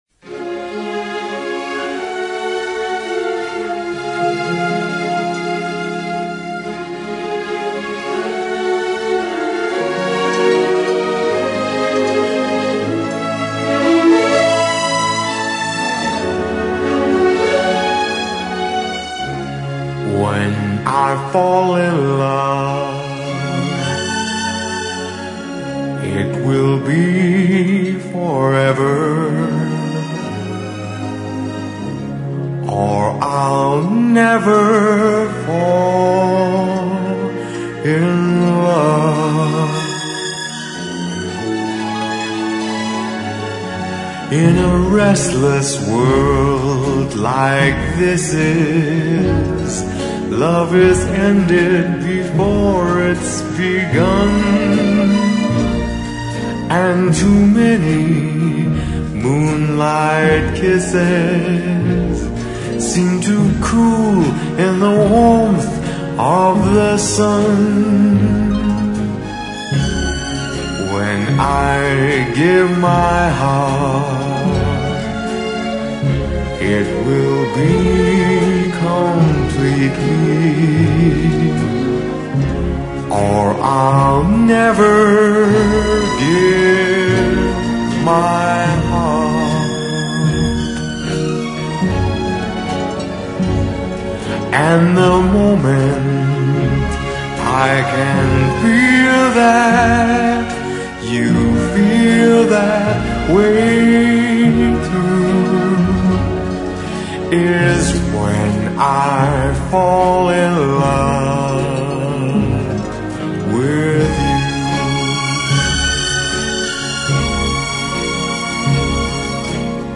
Rock Internacional Para Ouvir: Clik na Musica.